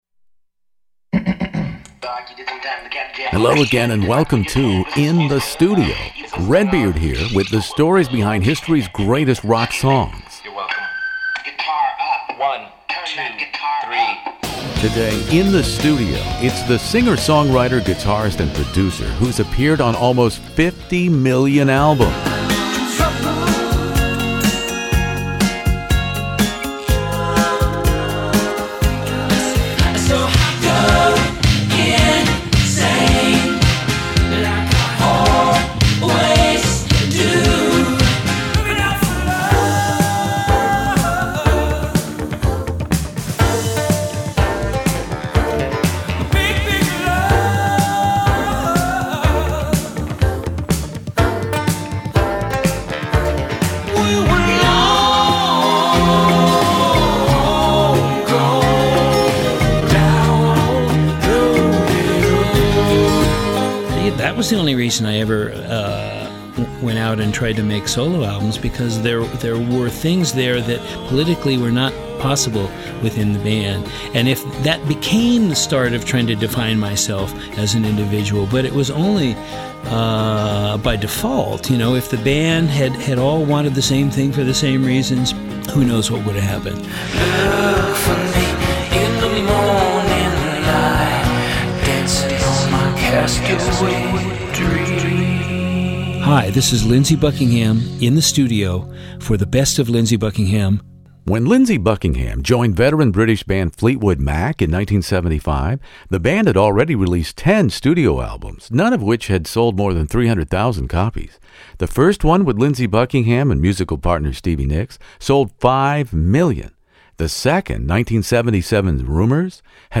One of the world's largest classic rock interview archives, from ACDC to ZZ Top, by award-winning radio personality Redbeard.
For two days, Buckingham and I sat in a small windowless studio serving as his confessional, his therapeutic safe space, and we did not leave until Lindsey told me his truth about playing the role of Vincent in the real-life Van Gogh soap opera that has been much of his life and musical career.